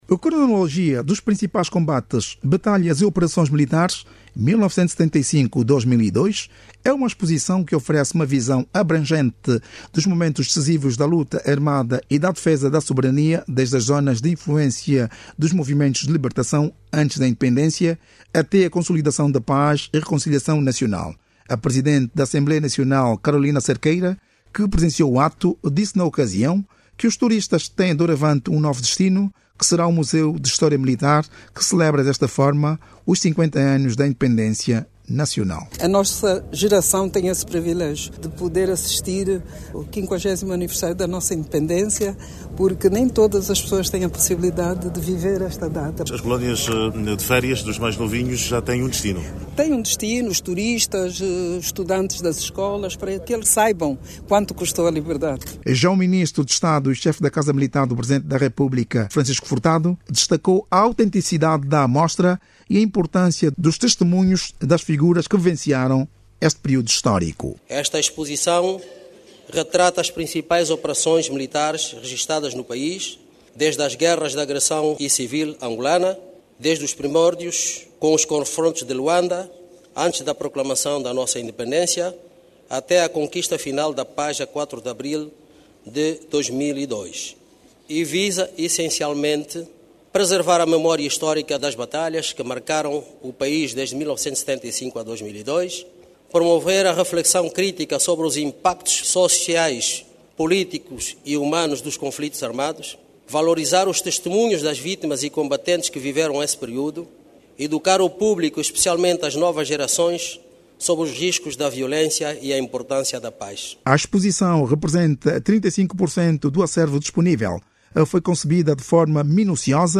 O Presidente da República João Lourenço prestigiou hoje, quinta-feira, 30, a inauguração de uma exposição que está em exibição no Museu de História Militar. A exposição, conformada por quadros multimédia, tem como tema de reflexão os Caminhos do Fogo e horizonte da Paz. Saiba mais dados no áudio abaixo com o repórter